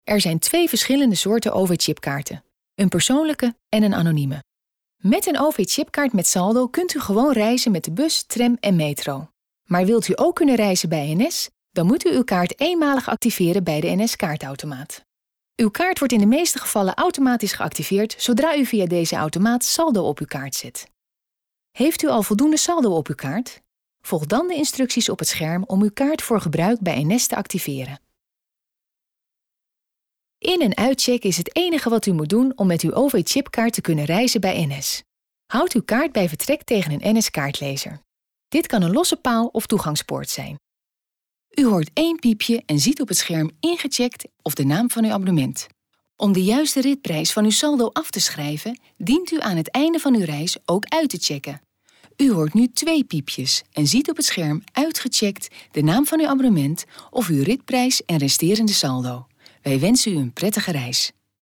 Commerciale, Fiable, Amicale, Chaude, Corporative
E-learning